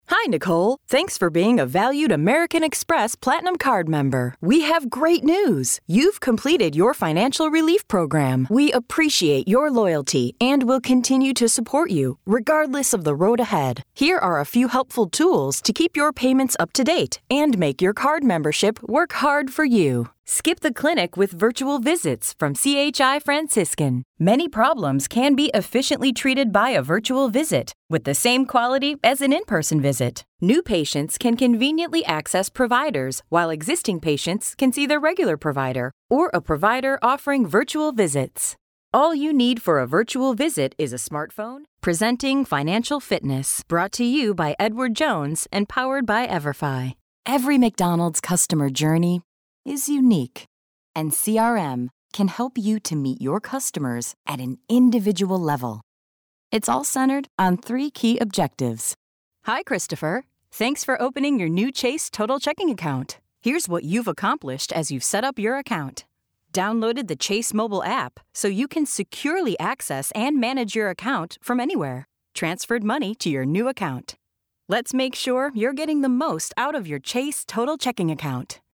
Vídeos corporativos
Micrófono MKH 415, Pro Tools, estudio insonorizado con funciones de masterización.